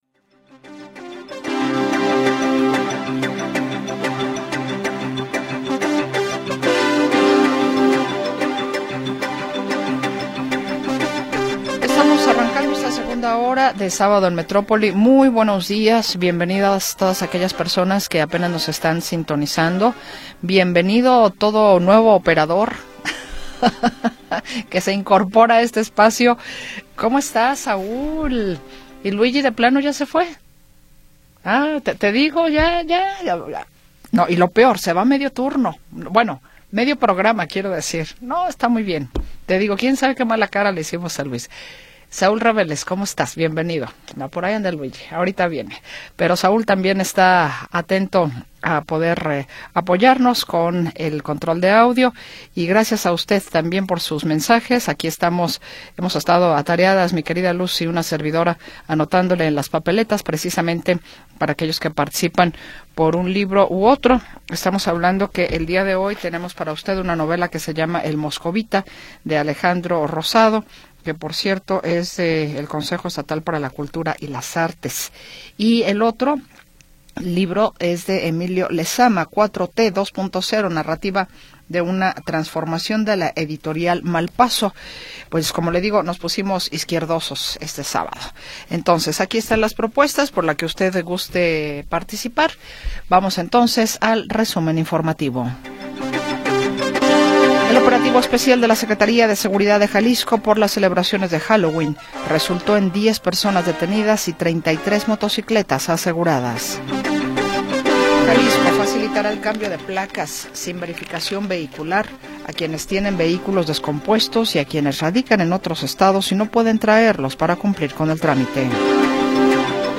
Segunda hora del programa transmitido el 1 de Noviembre de 2025.
1 de Noviembre de 2025 audio Noticias y entrevistas sobre sucesos del momento